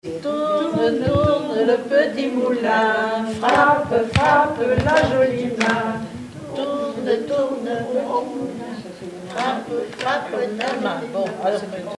formulette enfantine : amusette
Chansons, formulettes enfantines
Pièce musicale inédite
Catégorie Pièce musicale inédite